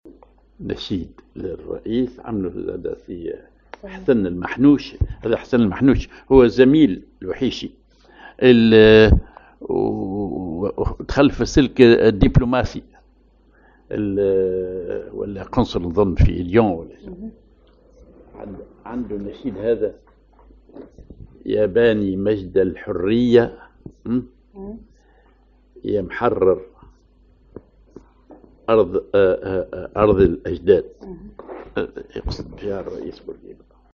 ar كردان
ar الخطوة